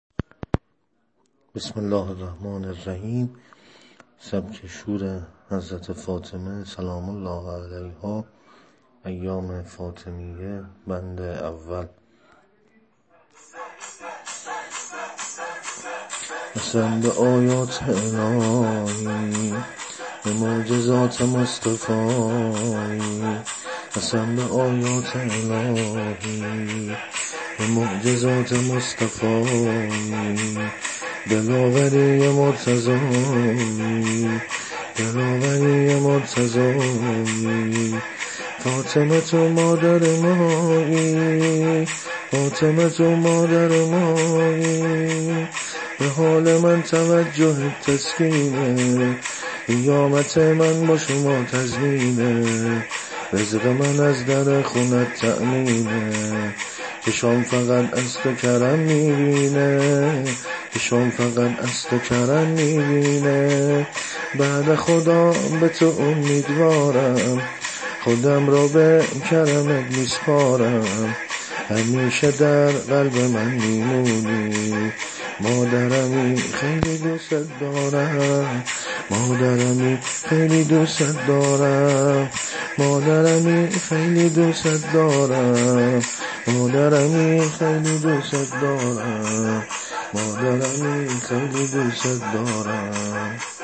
سبک شور حضرت فاطمه زهرا سلام الله علیها -(قسم به آیات الهی)